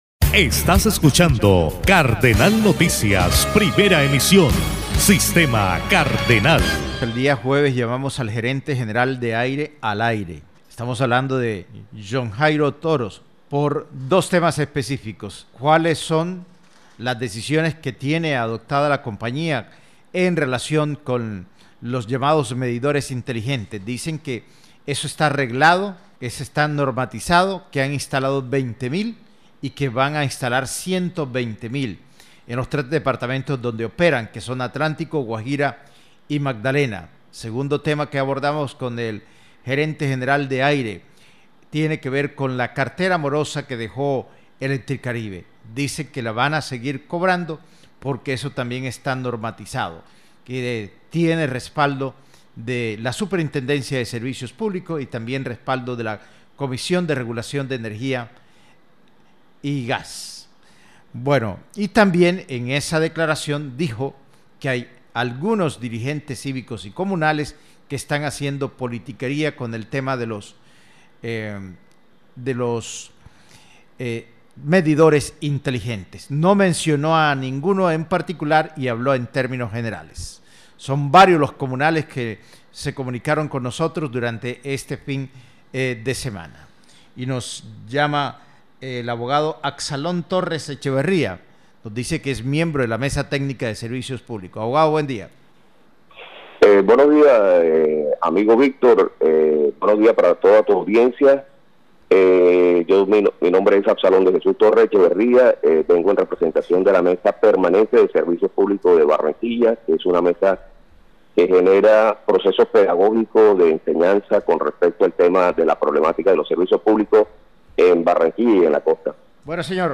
una entrevista para Sistema Cardenal 1010 AM.